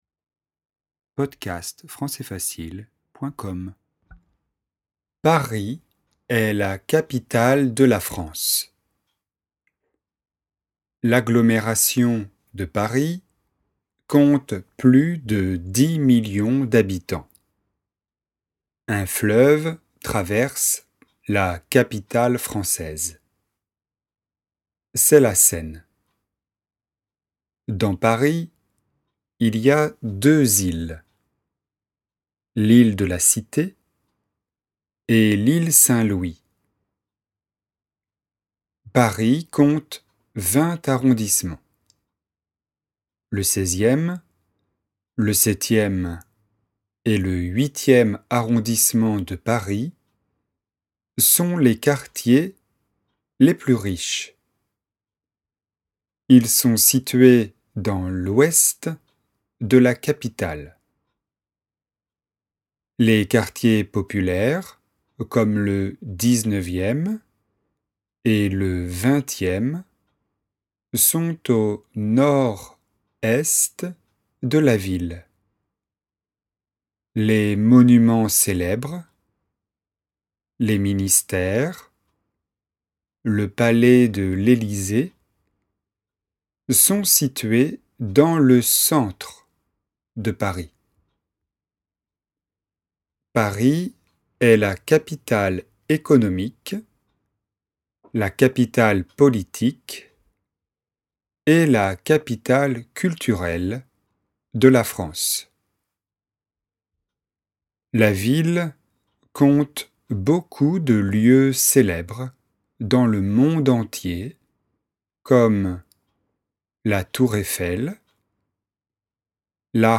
TEXTE : deux lectures (1 vitesse lente + 1 vitesse normale)